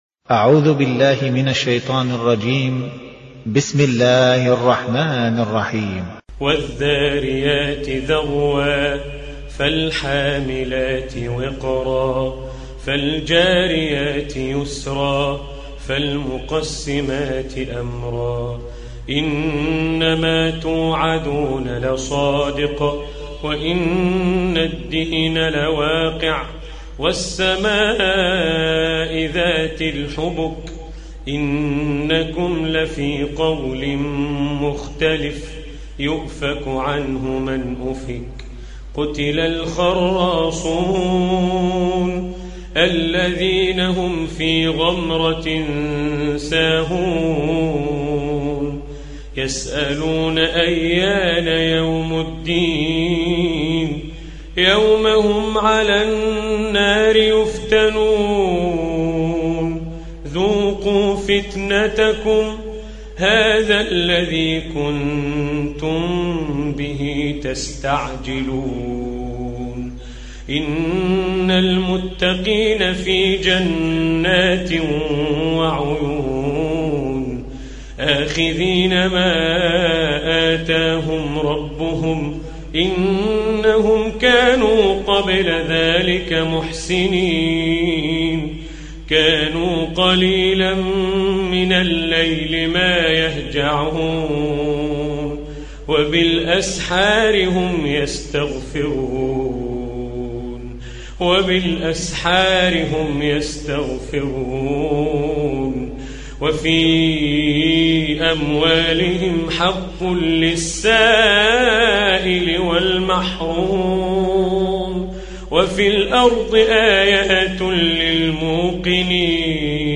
Чтение Корана